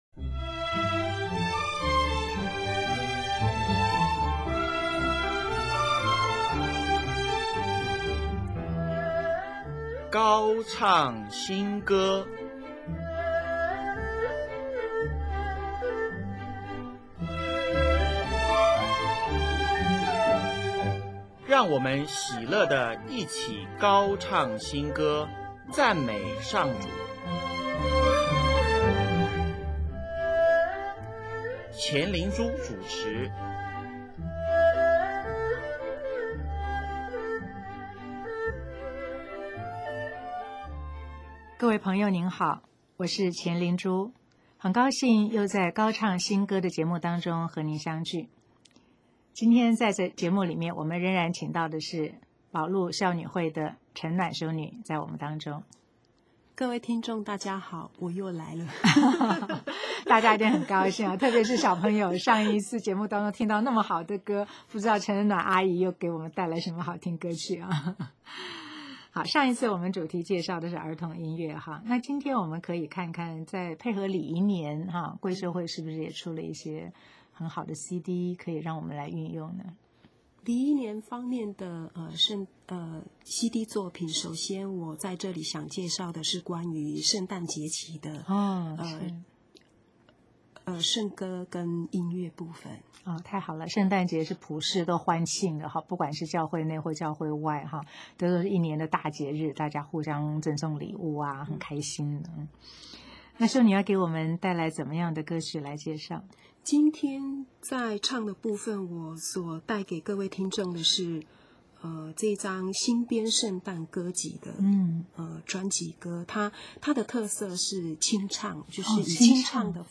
“圣诞交响乐”专集，本集播放“请受我祝福”，由上海交响乐团演奏。 “阿肋路亚”专集，本集播放同名歌曲，由中央合唱团清唱。